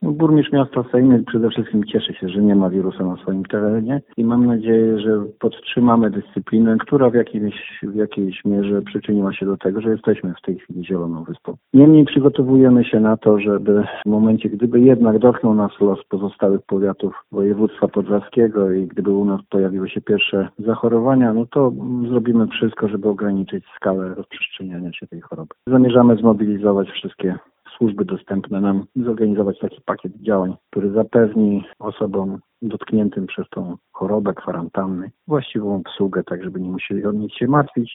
Arkadiusz Nowalski, burmistrz Sejn, cieszy się, że choroba nie dotknęła żadnego z mieszkańców.